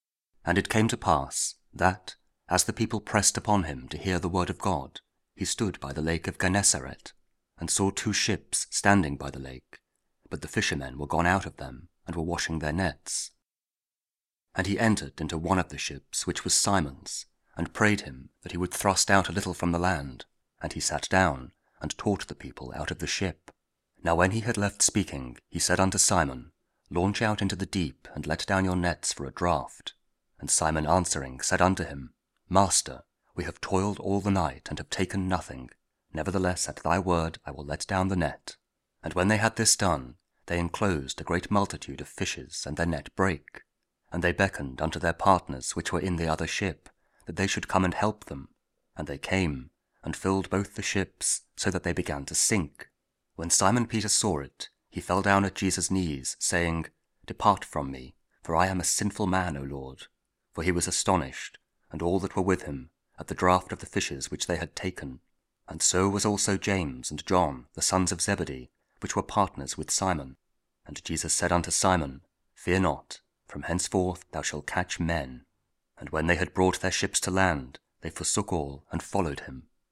Luke 5: 1-11 – Week 22 Ordinary Time, Thursday (King James Audio Bible KJV, Spoken Word)